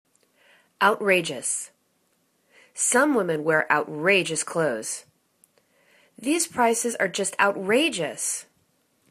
out.ra.geous      /out'ra:djəs/    adj